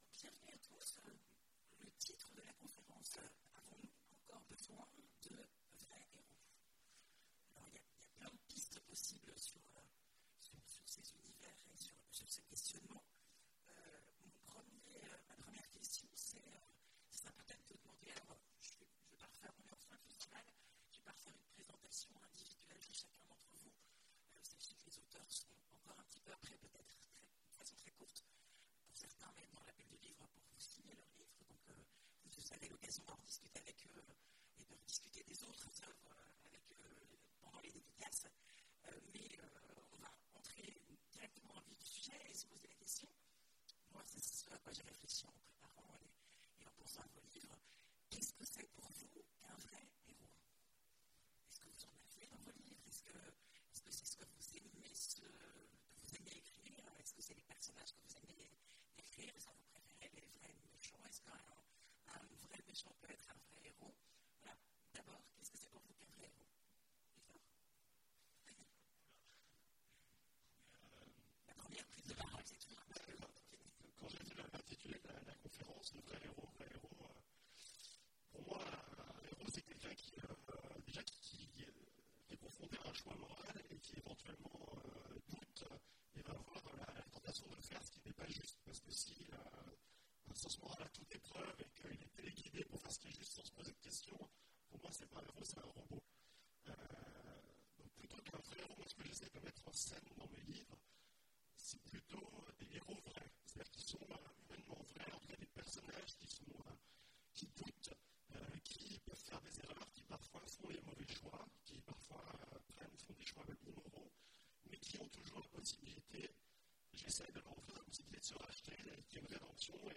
Imaginales 2017 : Conférence Avons-nous encore besoin… de vrais héros ?